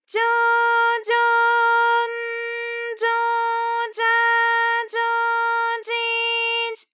ALYS-DB-001-JPN - First Japanese UTAU vocal library of ALYS.
jo_jo_n_jo_ja_jo_ji_j.wav